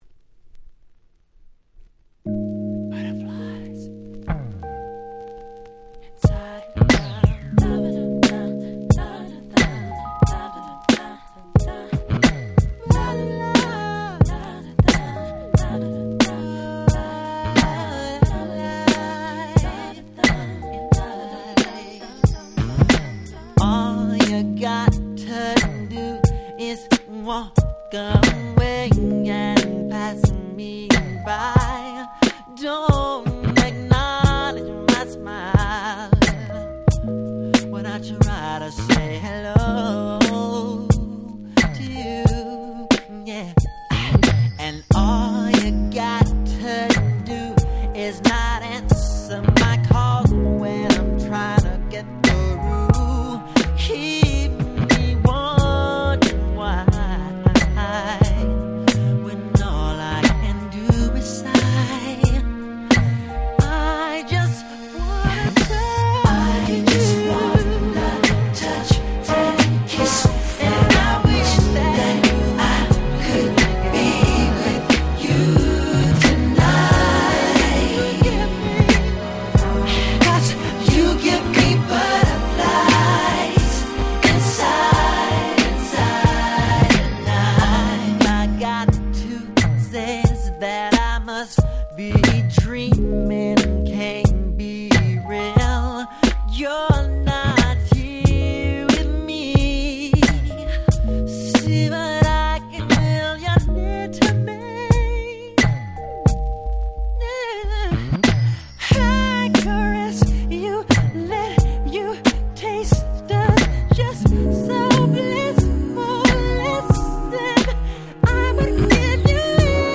01' Nice R&B !!
最高のNeo Soul / R&Bナンバーに仕上がっております！！